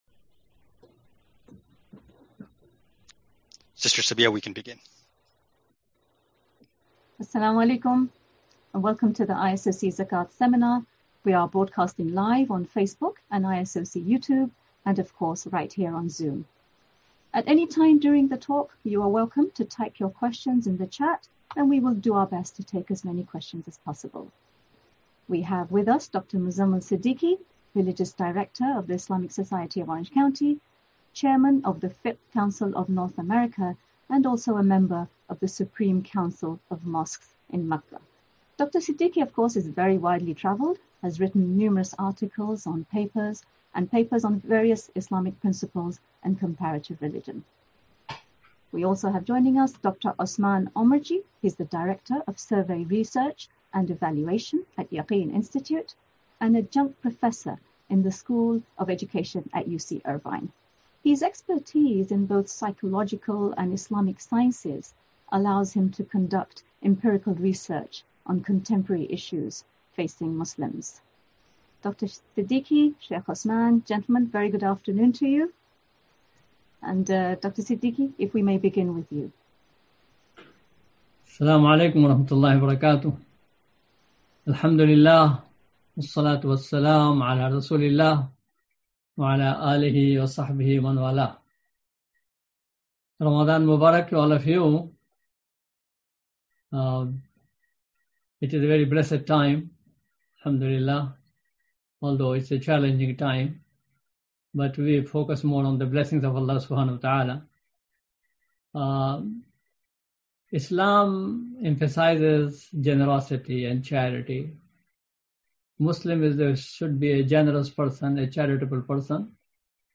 Zakat Seminar